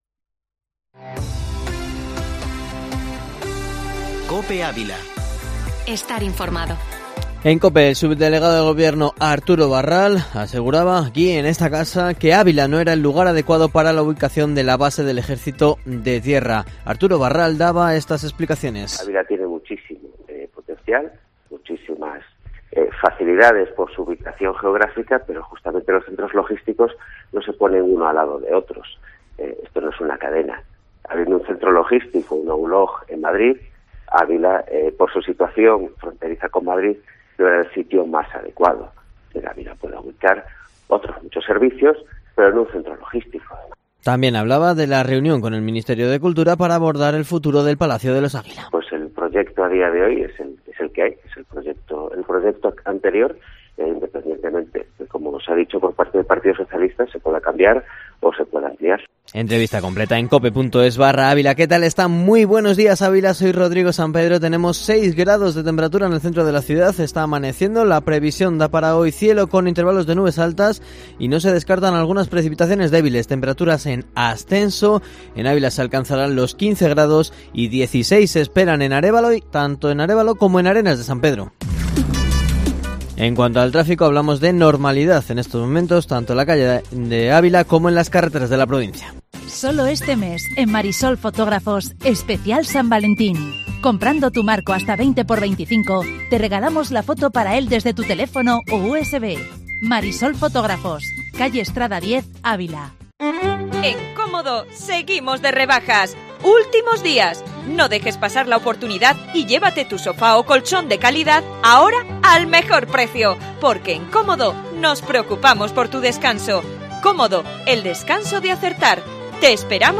informativo local y provincial